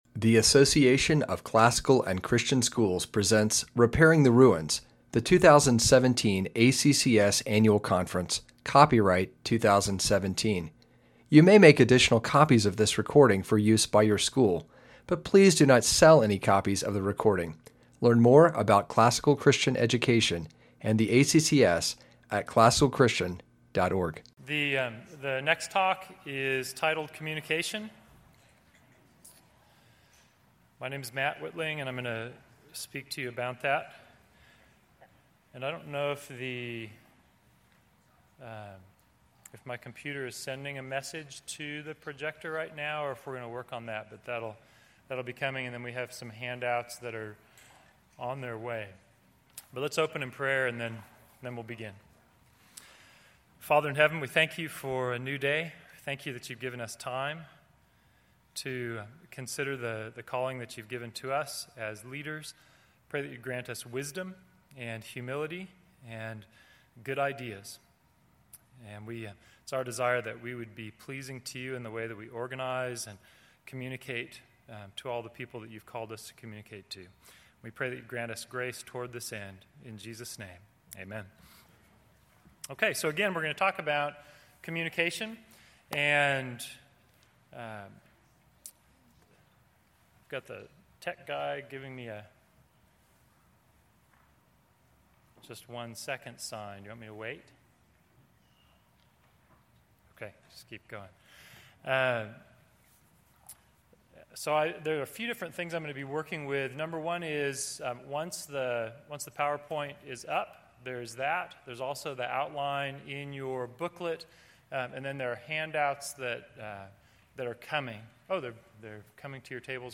2017 Leaders Day Talk | 0:38:23 | All Grade Levels, Leadership & Strategic